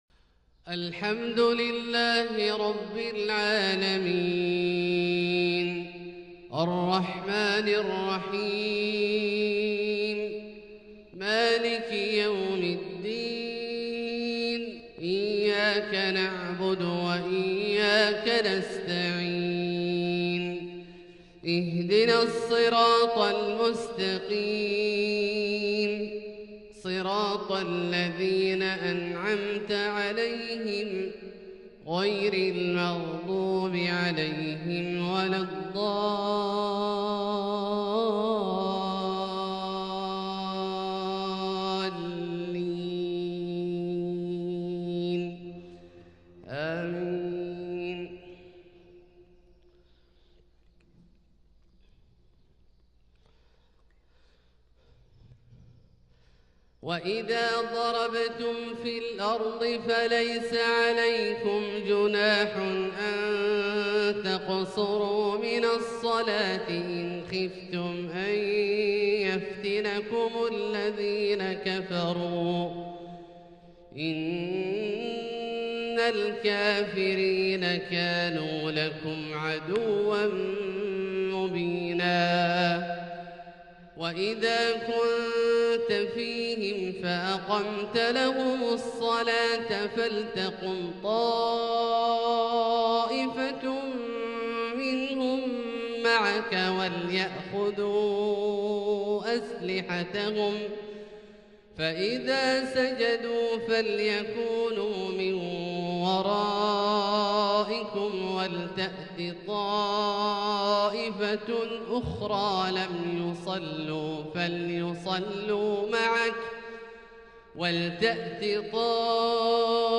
فجر 6-7-1442هـ | سورة النساء (101-114) > ١٤٤٢ هـ > الفروض - تلاوات عبدالله الجهني